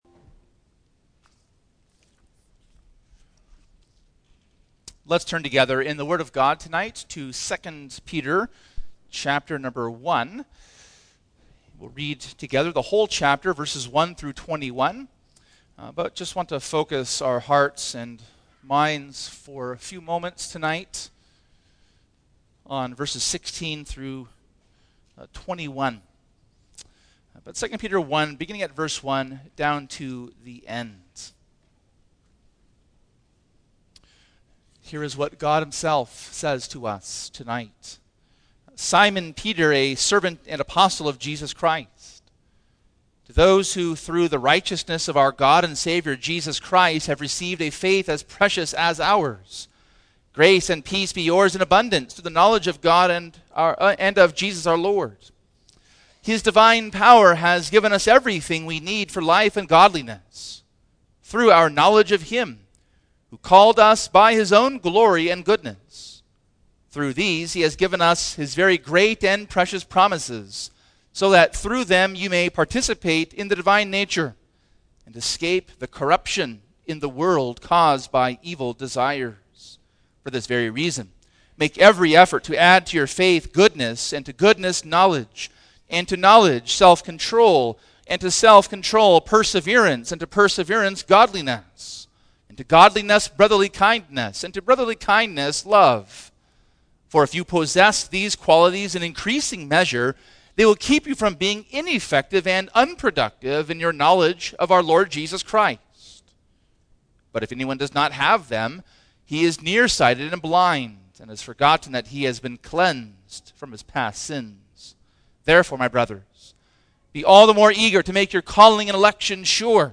Single Sermons Passage: 2 Peter 1:1-21